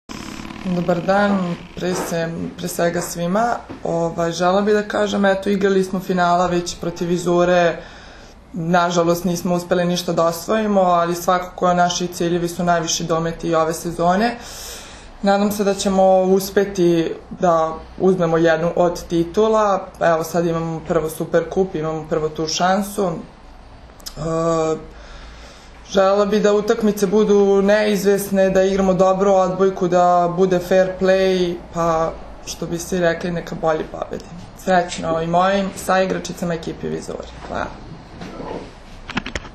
U prostorijama Odbojkaškog saveza Srbije danas je održana konferencija za novinare povodom utakmice IV Super Kupa Srbije 2016. u konkurenciji odbojkašica, koja će se odigrati sutra (četvrtak, 13. oktobar) od 18,00 časova u dvorani SC “Vizura” u Beogradu, između Vizure i Jedinstva iz Stare Pazove, uz direktan prenos na RTS 2.
IZJAVA